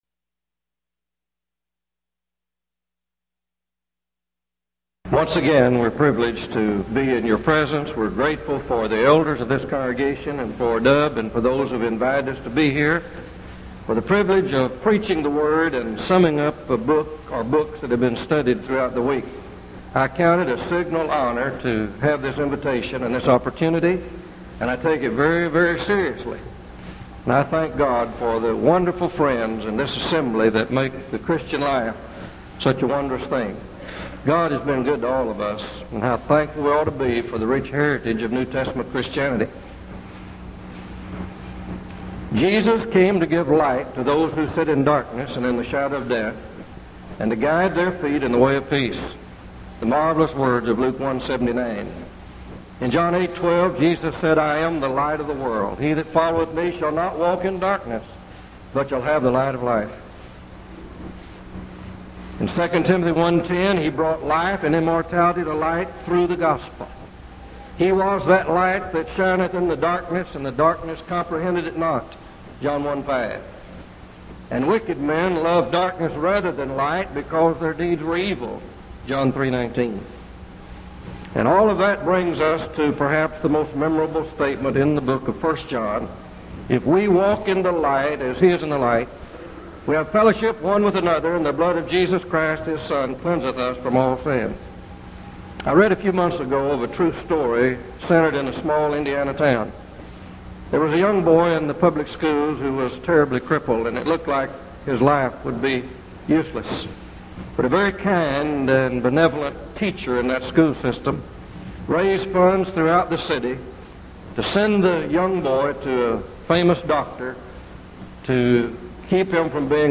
Event: 1987 Denton Lectures
If you would like to order audio or video copies of this lecture, please contact our office and reference asset: 1987Denton35